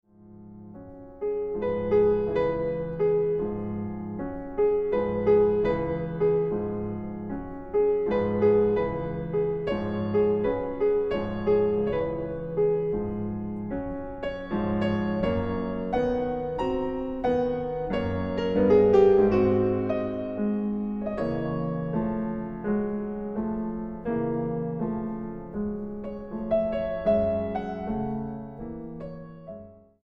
Andante con Moto e Poco Rubato 03:39
piano music